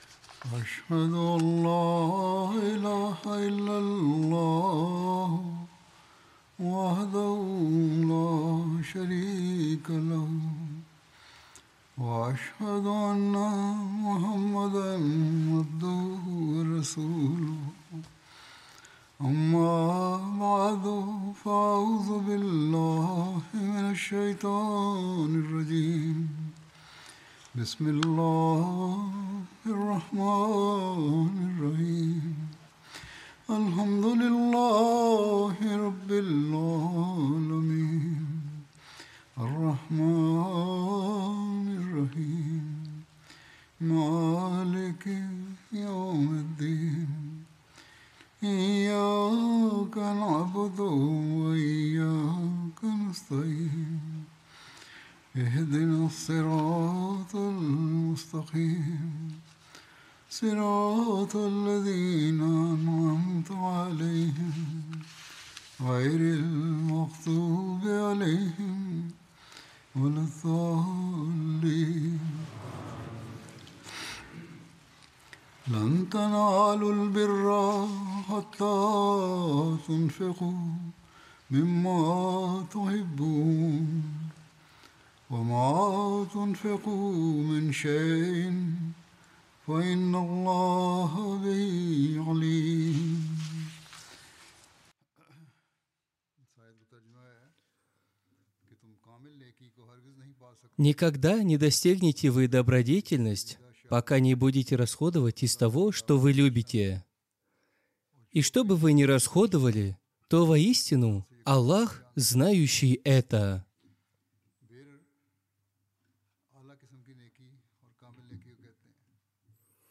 Russian Translation of Friday Sermon delivered by Khalifatul Masih